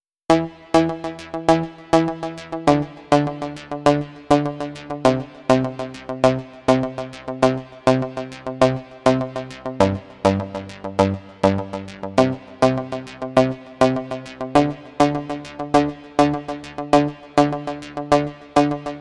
只需5秒钟 空间琶音
E D C G C D (对不起，101 BPM)
标签： 101 bpm Chill Out Loops Synth Loops 3.20 MB wav Key : Unknown
声道立体声